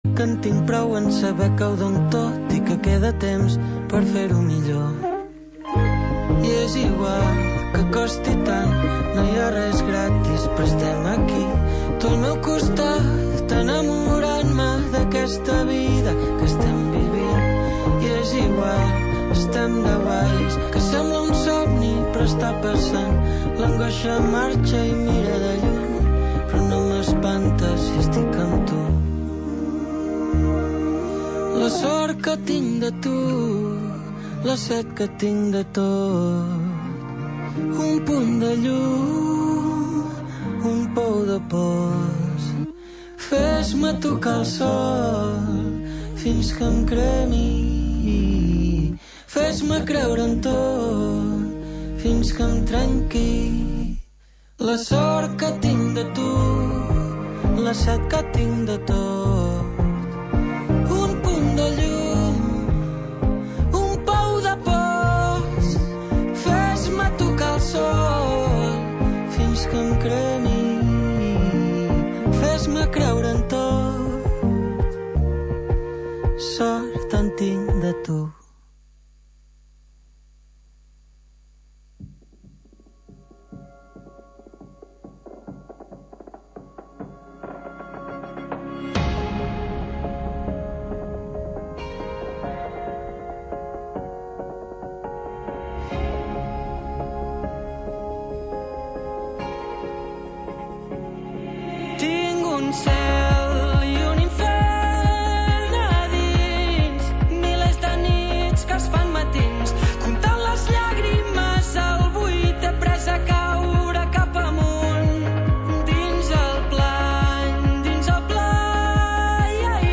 L'alcalde de l'Escala a disposició dels oients